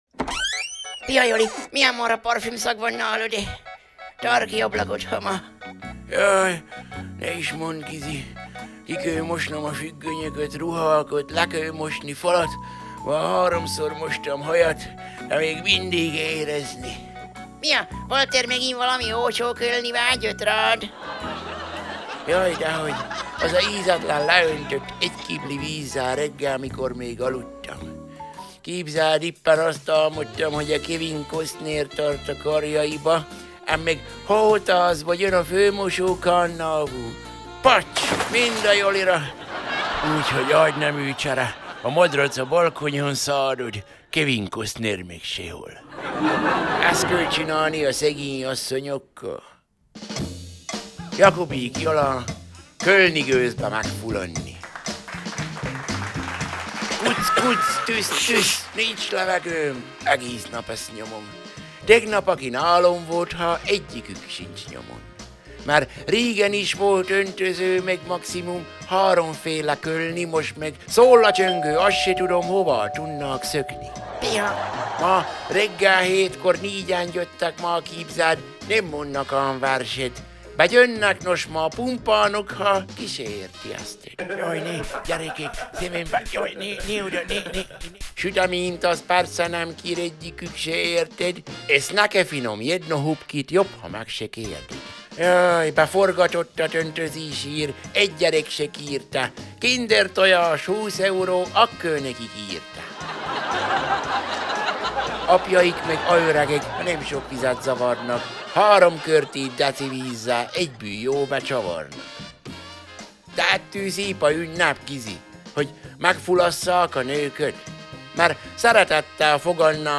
Szaval a jó öreg csallóközi
Zene: Billy May & His Orchestra - The Odd Couple